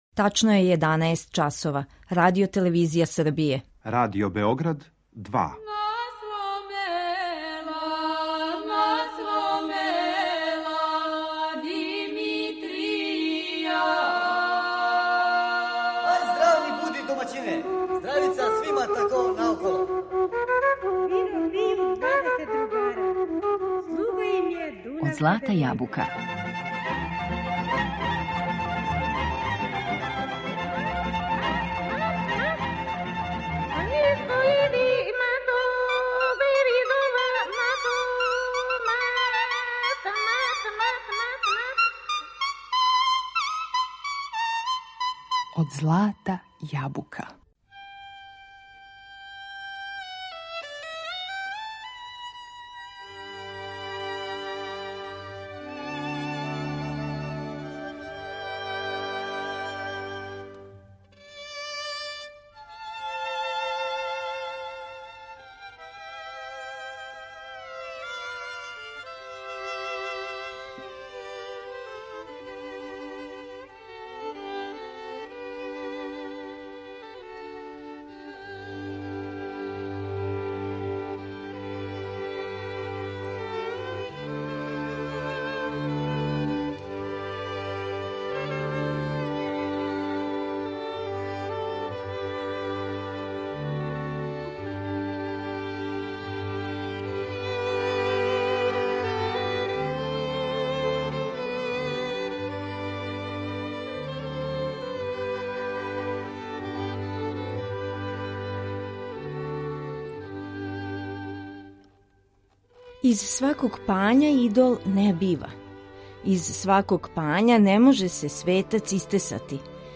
Шта су све атрибути пања у нашој народној култури, чућете у данашњој емисији Од злата јабука . На репертоару су најлепше песме и мелодије у инструменталном извођењу нашег Народног оркестра.